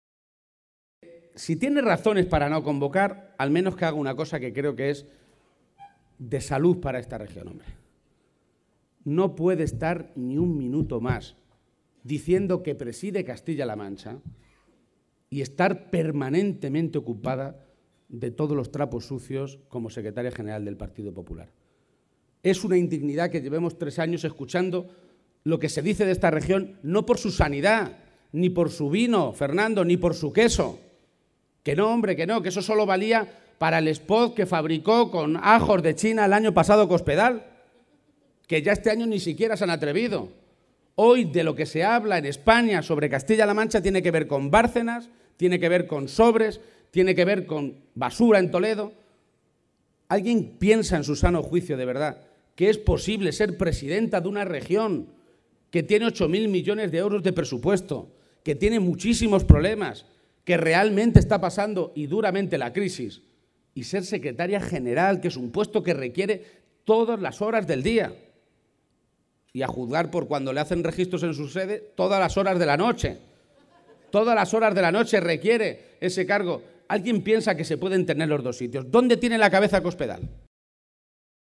El secretario general del PSCM-PSOE presidió la tradicional comida navideña con militantes socialistas de Albacete
Audio Page Comida Navidad Albacete_211213